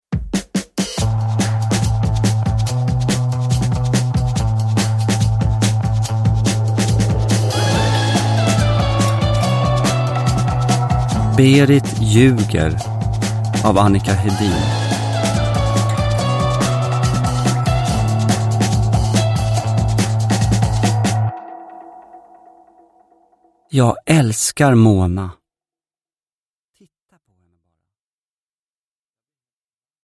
Berit ljuger – Ljudbok – Laddas ner
Uppläsare: Gustaf Hammarsten